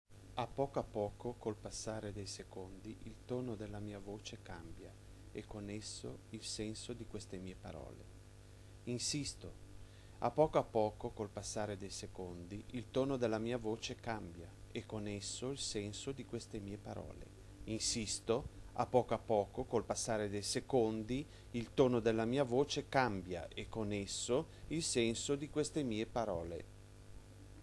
La poesia sonora si collega per un verso alla musica e per un altro al teatro, da un lato abbina il testo poetico ad un brano musicale, dall'altro sfrutta la sonorità del linguaggio.